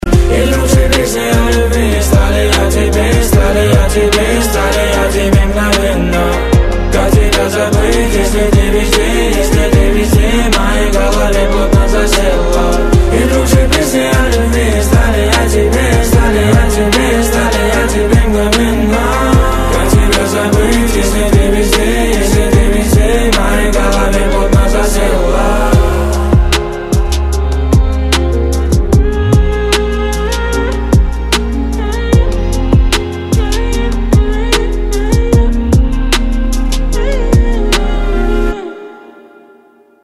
Категория: Рэп рингтоны